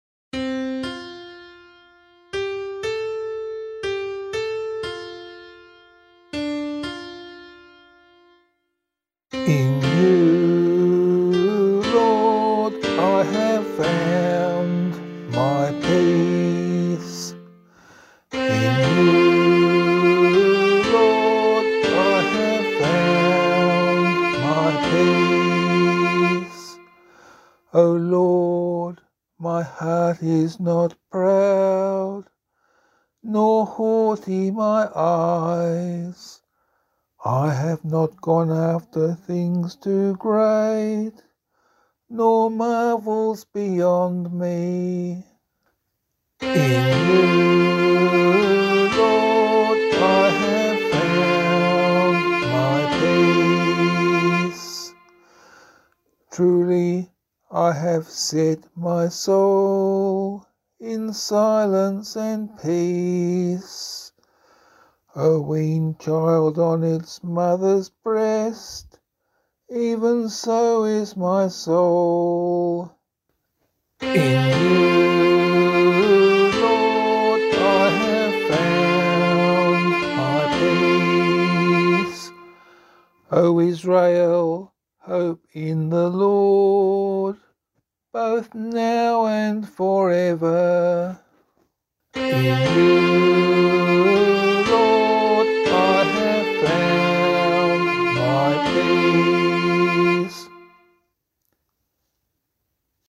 The demo transposes the sheet music from B minor to G minor.